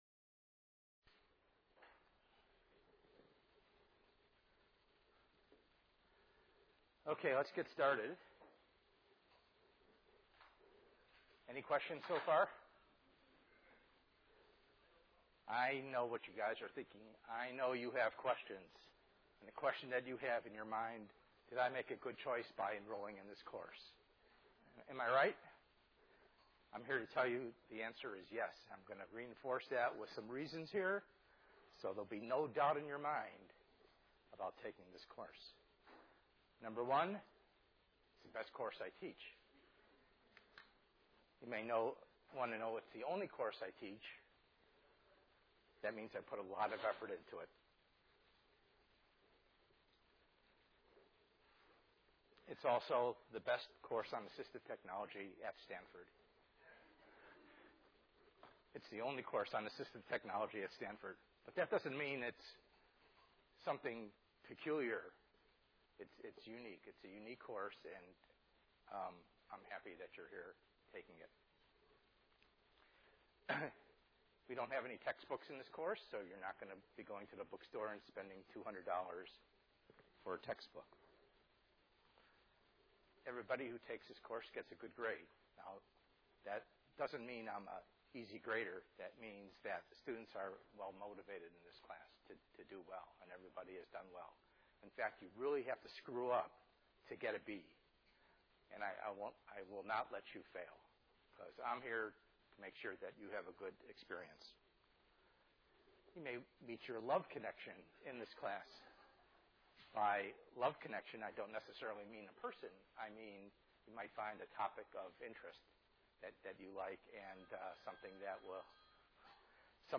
ENGR110/210: Perspectives in Assistive Technology - Lecture 01a